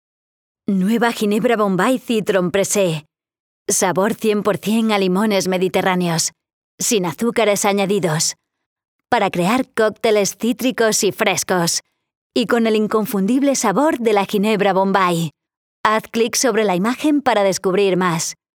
Imágenes de radio